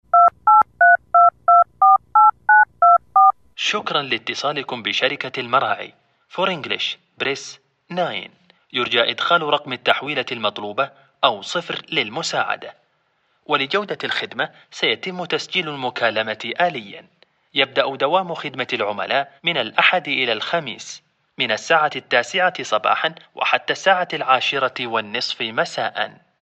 تعليق صوتي - رد آلي
عمل رد آلي لشركة المراعي